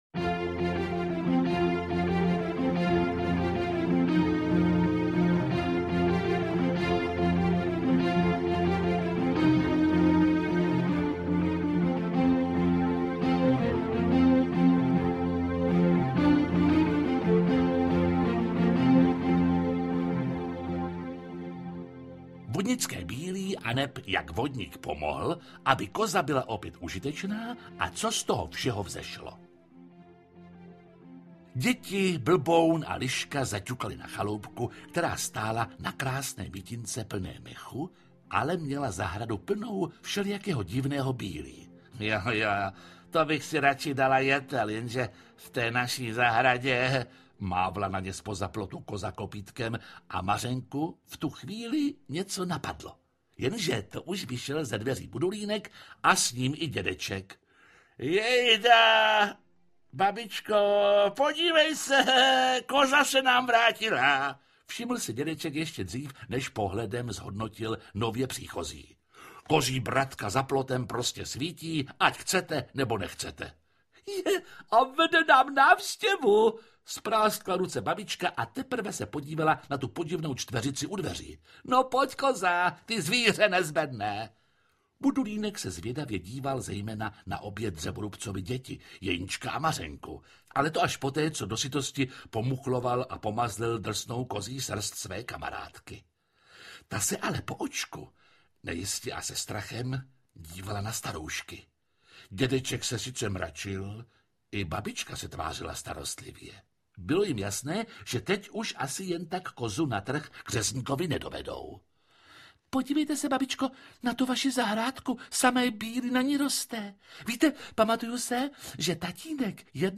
Audiokniha
Čte: Jiří Lábus